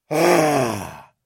MONSTER horror sounds " 00826 zombie hit 1
描述：通过Neumann TLM 103和Tl Audio C1
Tag: 呻吟 命中 号啕大哭 男人 怪物 呼喊 僵尸